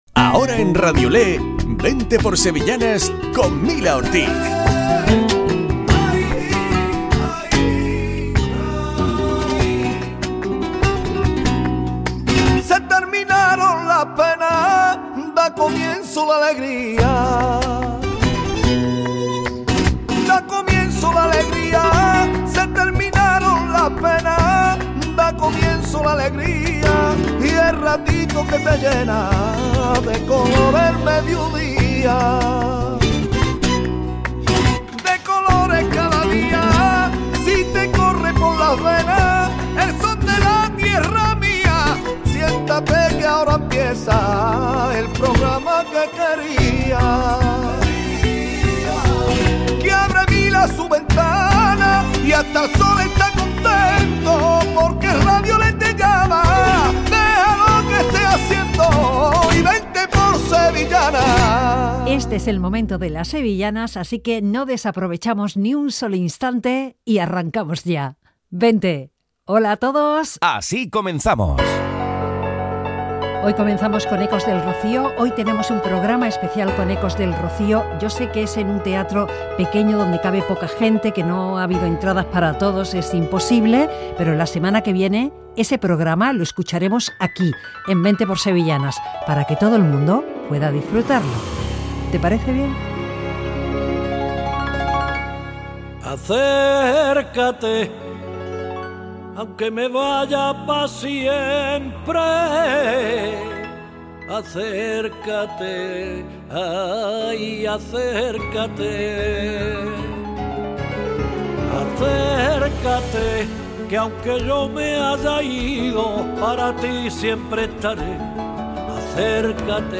Programa dedicado a las sevillanas.